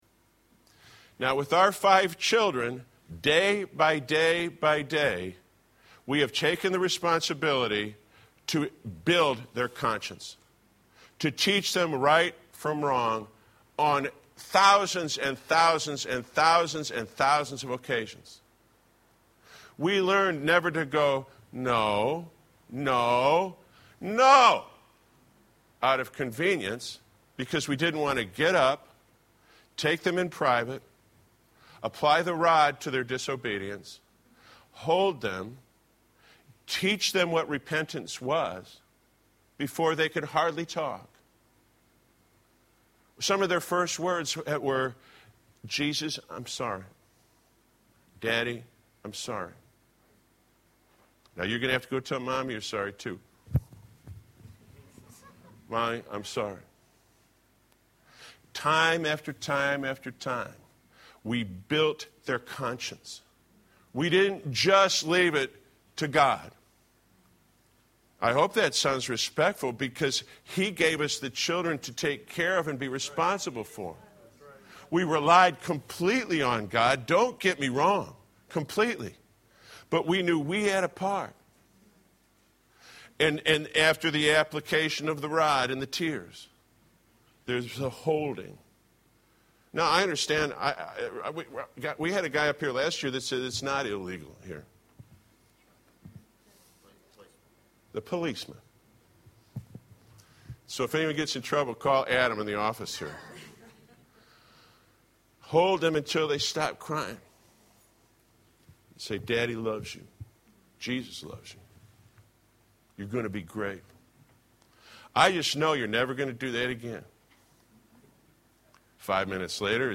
An except from a sermon